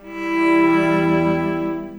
Cinematic 27 Strings 05.wav